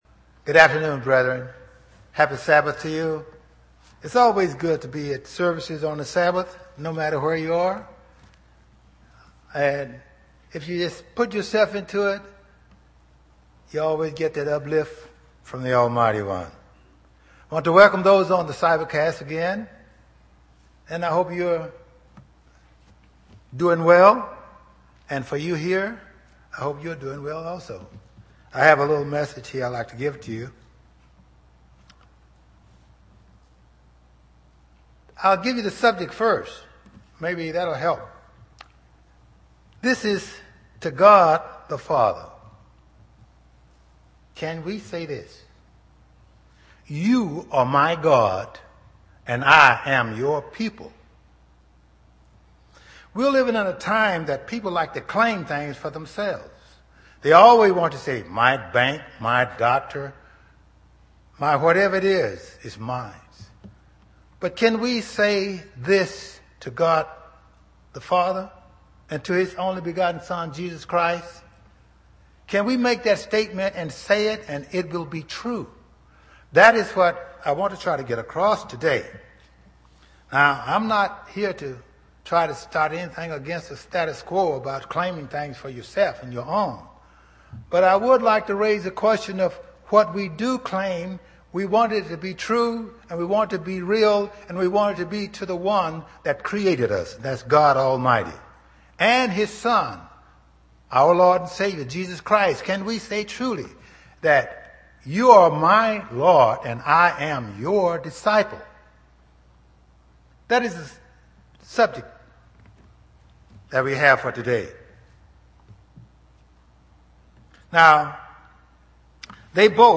Given in East Texas
UCG Sermon Studying the bible?